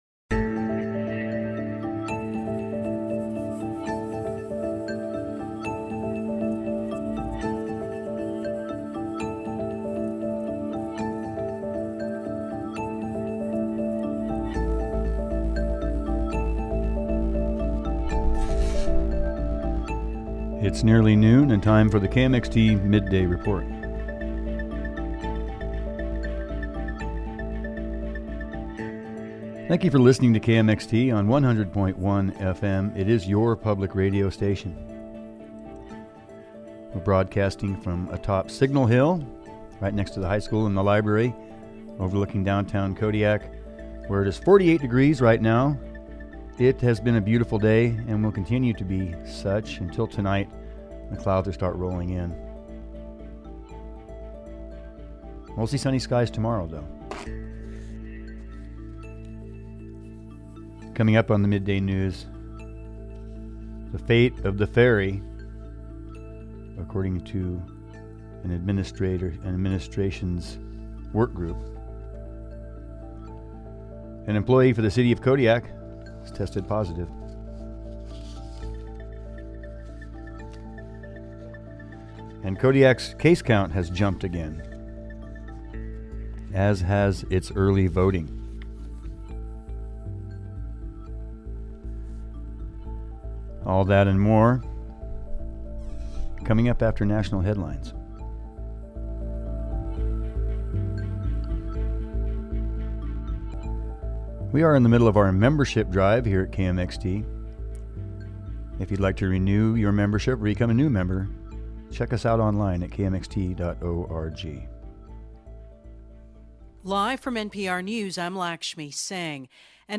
Stay tuned for this Monday’s midday news report